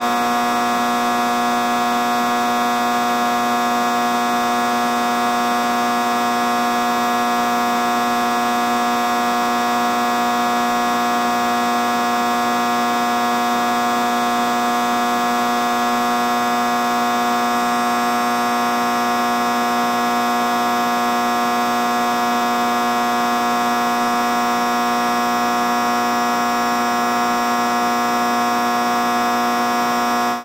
Шум трансформатора в гараже